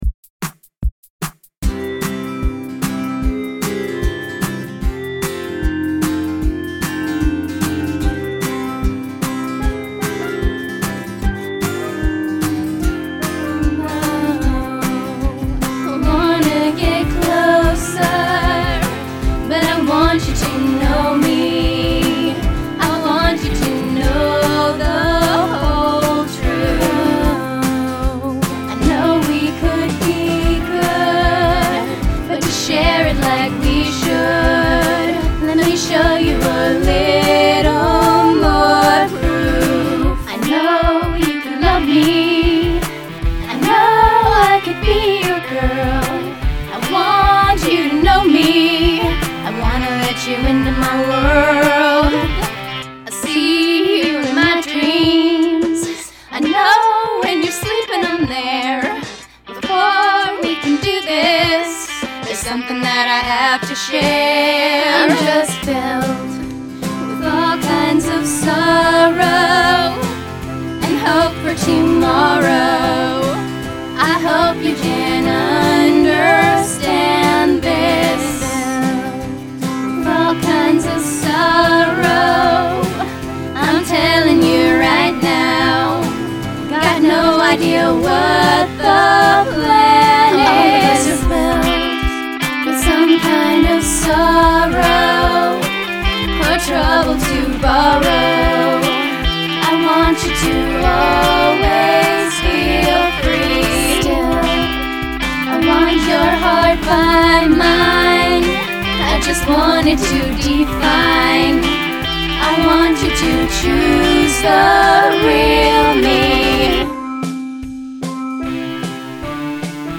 Posted in Other, Vocals Comments Off on